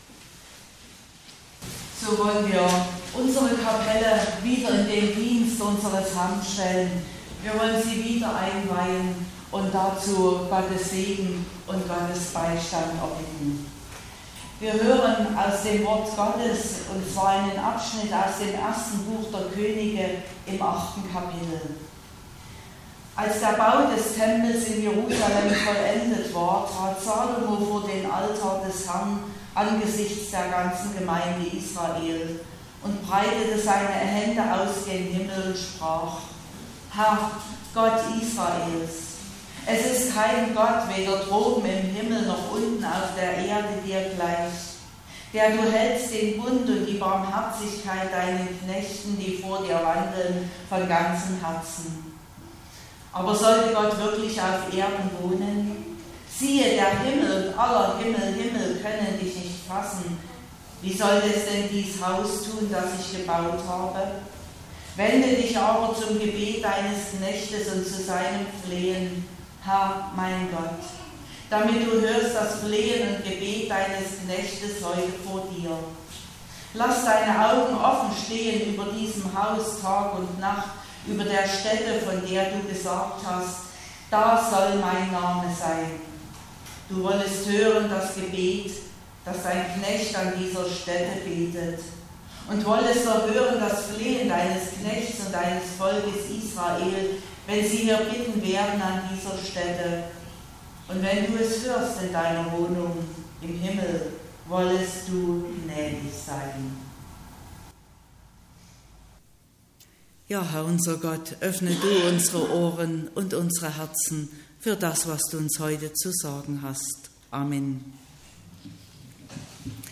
31.10.2021 – Gottesdienst
Predigt und Aufzeichnungen